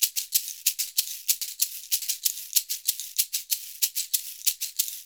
Index of /90_sSampleCDs/USB Soundscan vol.56 - Modern Percussion Loops [AKAI] 1CD/Partition B/07-SHAKER094